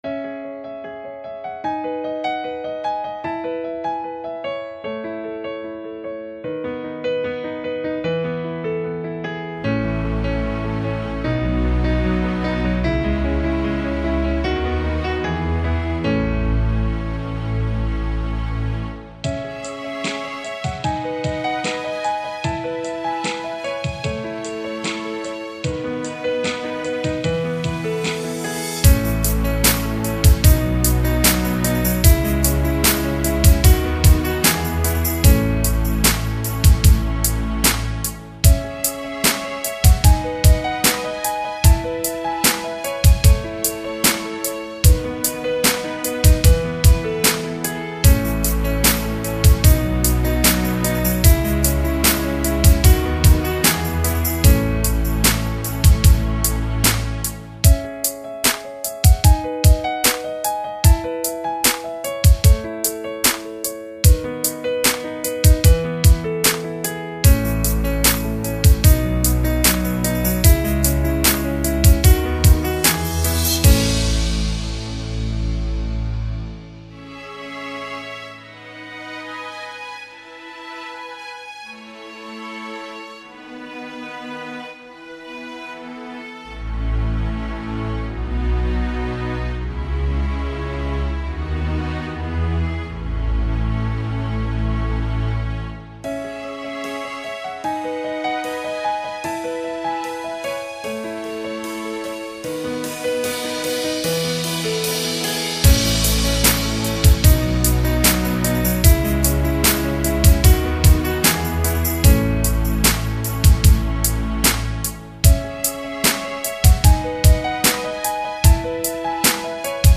新世纪钢琴
音乐风格: New Age / Instrumental
无论音乐的主调还是钢琴、弦乐的搭配都到达了和谐境界，把大家带到美的梦境之中。